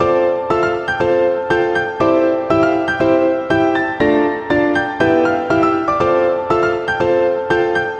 描述：我制作的简单的大钢琴旋律。
Tag: 120 bpm Chill Out Loops Piano Loops 1.35 MB wav Key : Unknown